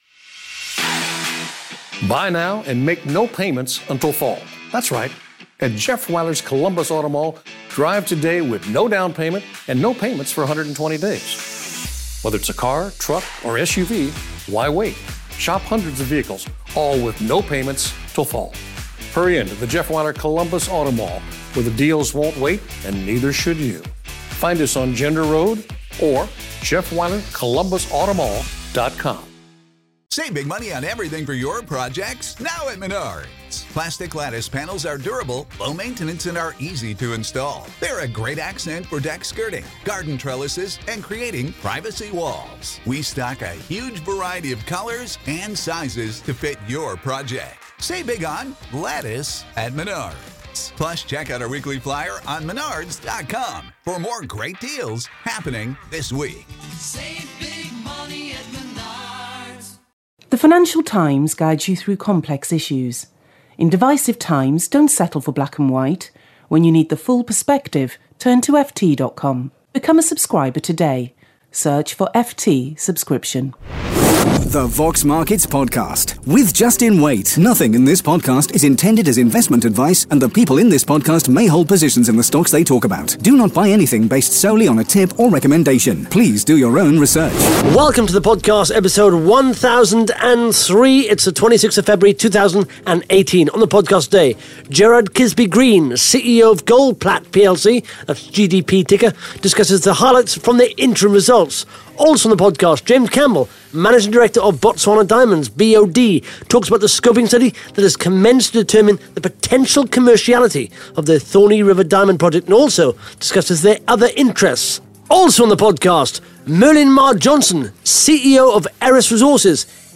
(Interview starts at 1 minute 28 seconds)